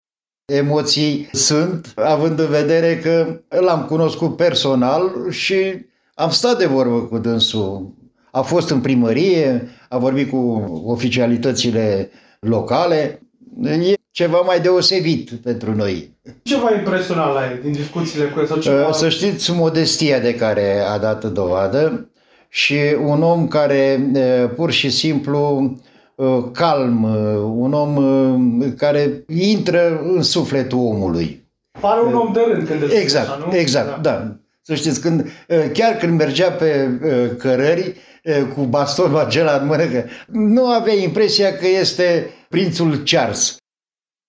Și la Primăria Bunești este agitație mare. Viceprimarul Dumitru Gărgărici: